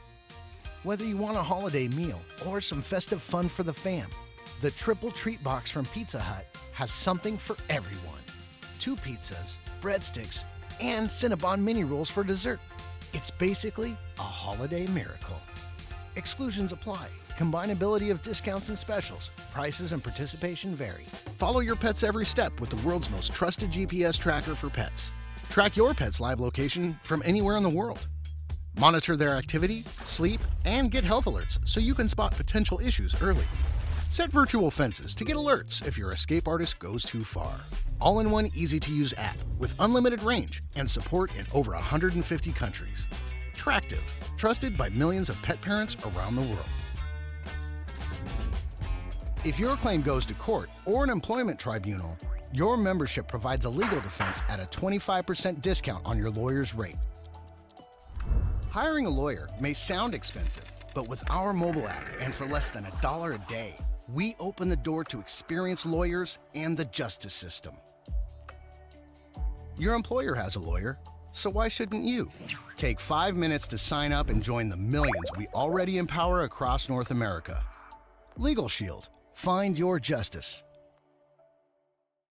Commercial Reel
Middle Aged
I have a broadcast quality home studio and love connecting for directed sessions.